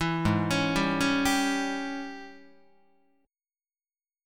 AbM7sus4#5 Chord